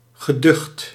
Ääntäminen
IPA: /ʁə.du.tabl/